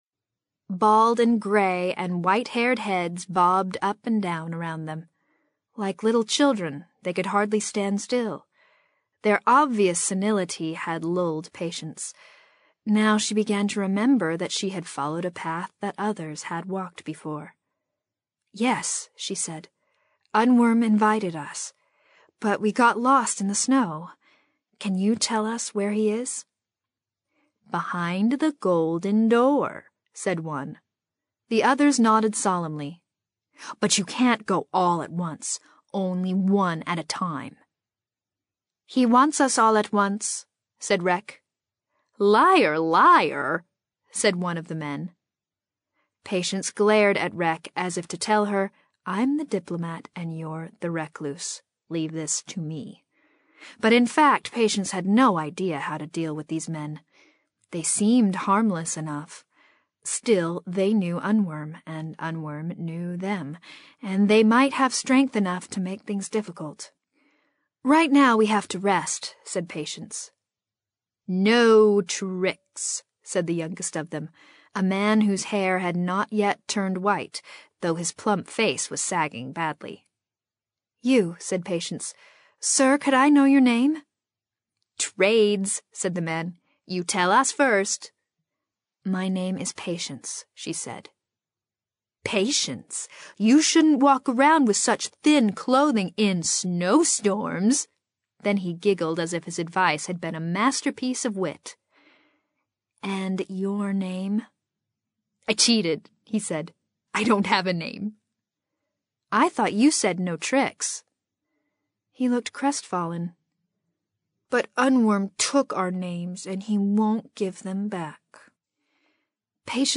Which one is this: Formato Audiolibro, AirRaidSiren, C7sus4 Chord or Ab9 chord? Formato Audiolibro